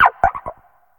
Cri de Nigirigon dans sa forme Affalée dans Pokémon HOME.
Cri_0978_Affalée_HOME.ogg